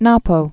(näpō)